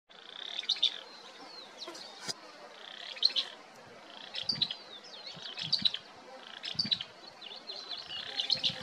Barullero (Euscarthmus meloryphus)
Nombre en inglés: Fulvous-crowned Scrub Tyrant
Fase de la vida: Adulto
Localidad o área protegida: Dique Luján
Condición: Silvestre
Certeza: Vocalización Grabada
barullero-audio.mp3